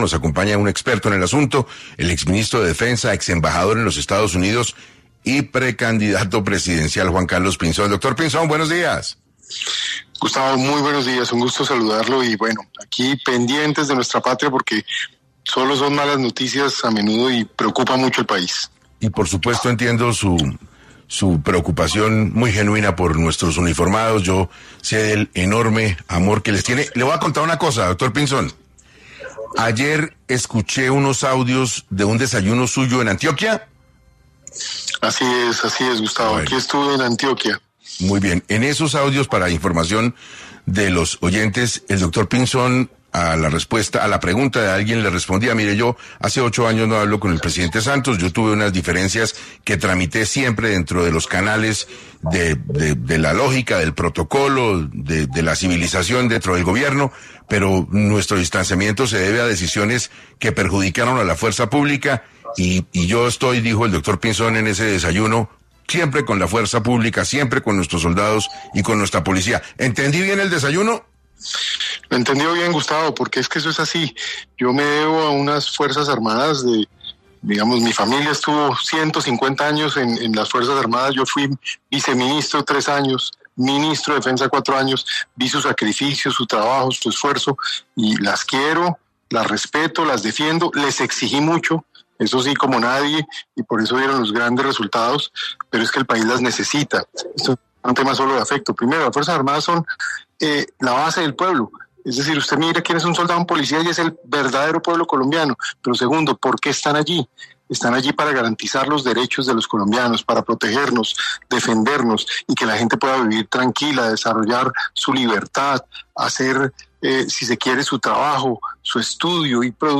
El precandidato presidencial Juan Carlos Pinzón habló en 6 AM de Caracol Radio